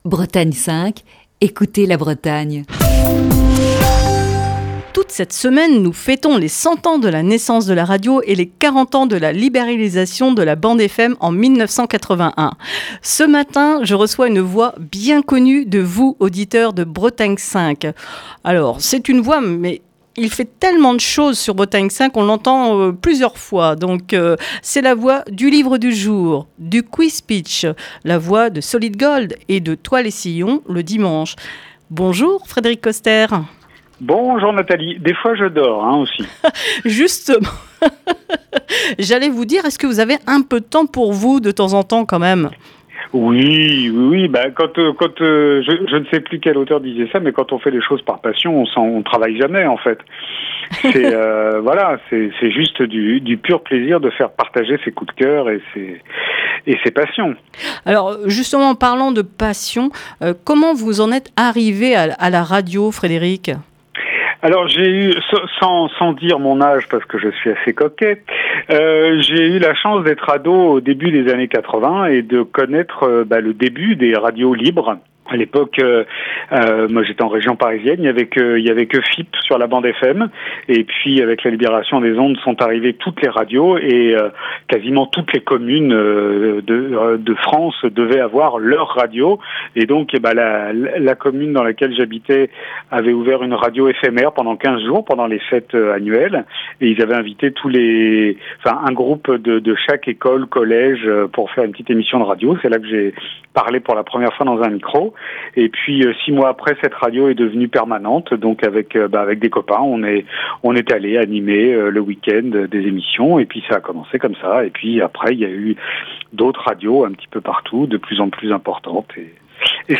Cette semaine, Le coup de fil du matin se met au diapason de la Fête de la Radio, avec les voix des animateurs et producteurs de Bretagne 5.